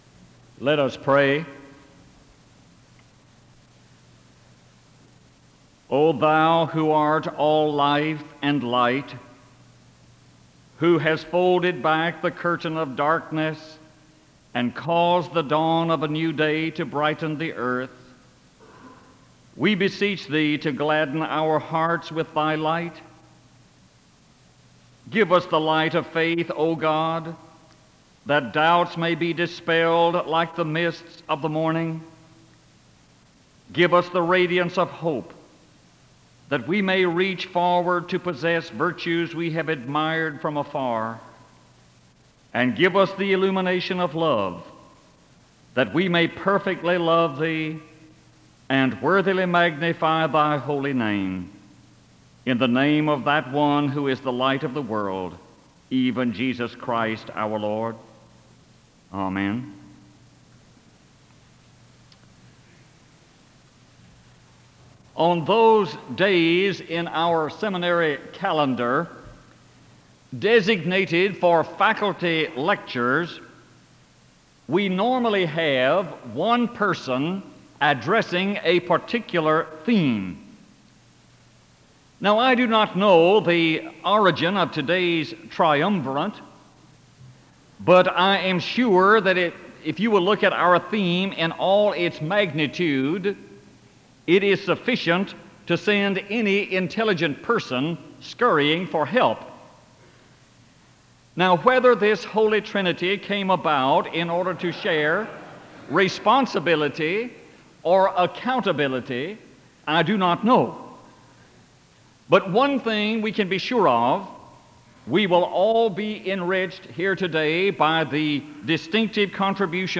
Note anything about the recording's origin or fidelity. The service begins with a word of prayer (00:00-01:00). The service ends with a benediction (45:03-45:43).